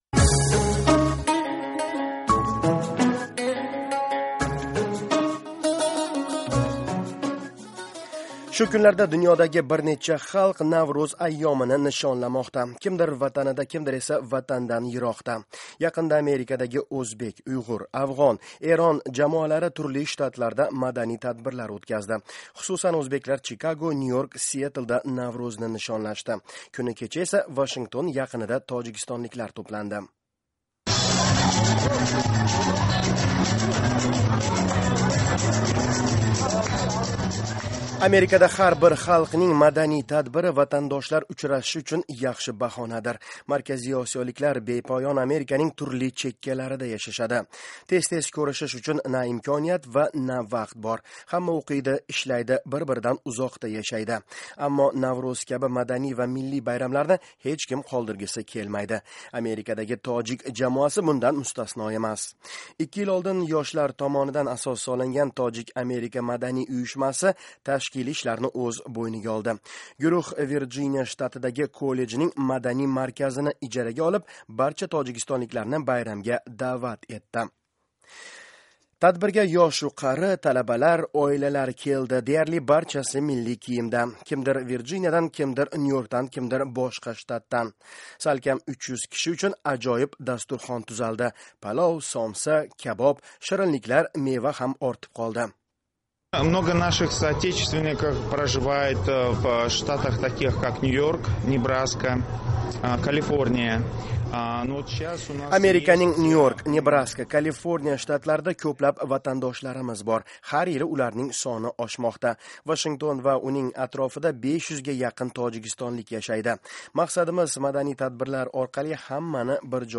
Virjiniyada tojikistonliklar Navro'zni nishonlamoqda, Shimoliy Virjiniya ijtimoiy kolleji, Annandeyl, Virjiniya, 23-mart, 2014-yil